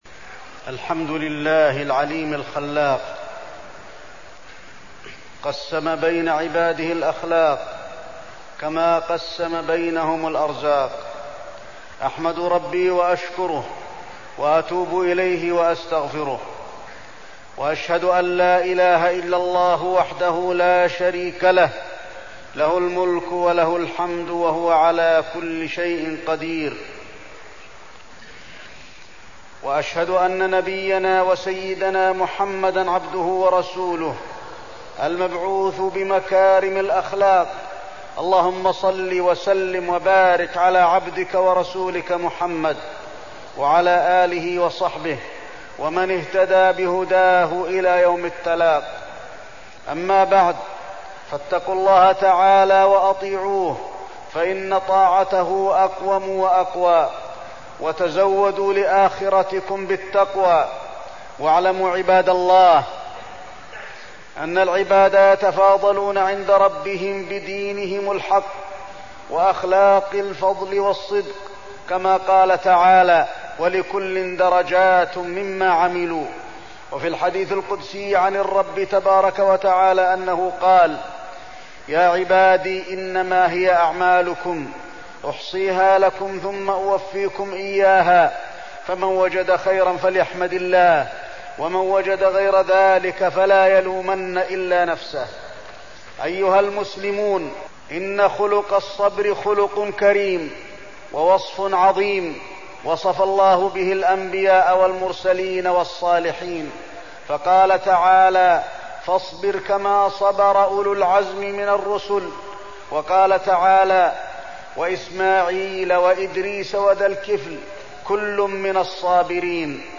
تاريخ النشر ٢٦ جمادى الأولى ١٤١٦ هـ المكان: المسجد النبوي الشيخ: فضيلة الشيخ د. علي بن عبدالرحمن الحذيفي فضيلة الشيخ د. علي بن عبدالرحمن الحذيفي الصبر